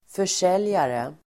Uttal: [för_s'el:jare]